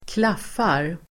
Ladda ner uttalet
Uttal: [²kl'af:ar]